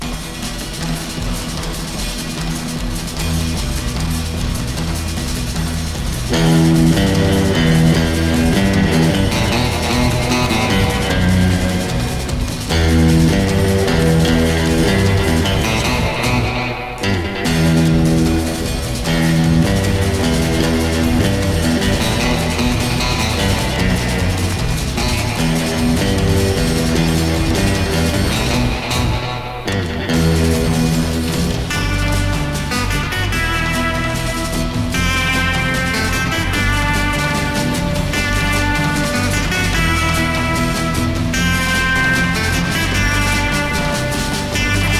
哀愁あるメロディを奏で、スペイシー感覚のエッセンスとして、エコーを利かせたサウンドが特徴。
Rock, Pop　France　12inchレコード　33rpm　Mono/Stereo